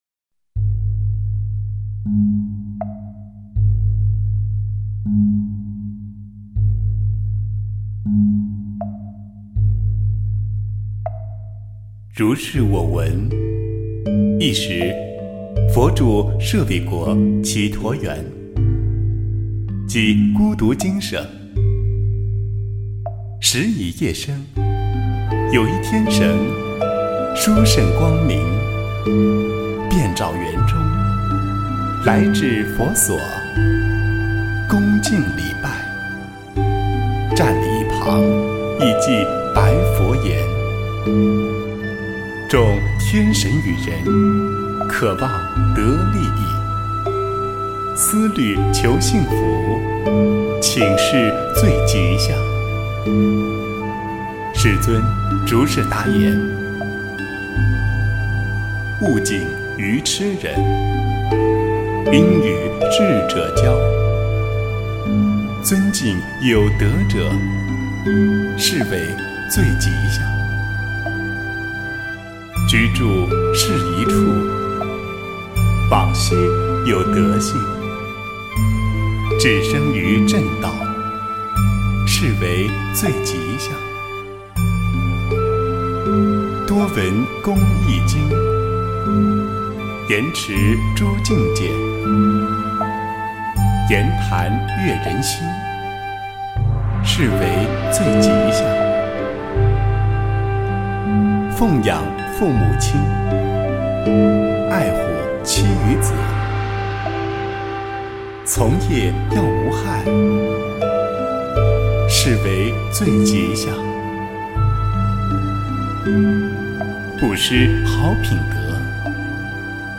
吉祥经（念诵）
诵经